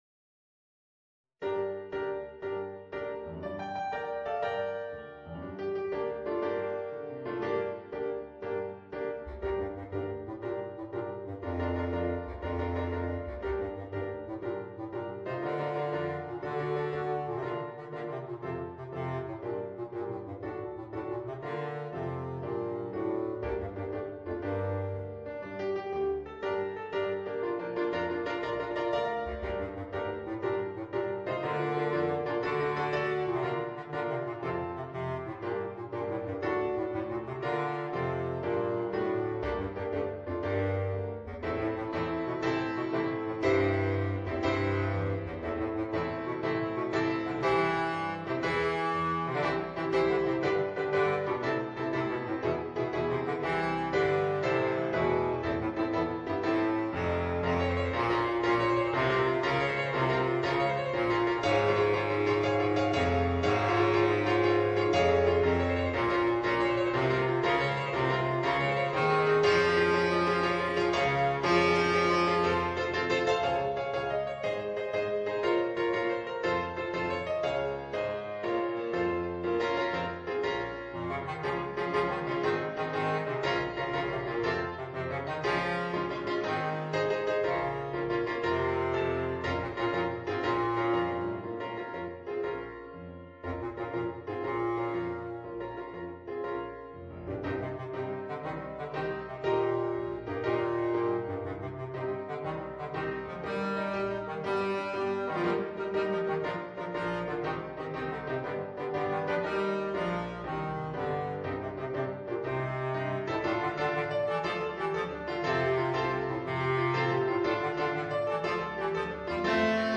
Voicing: Bass Clarinet and Piano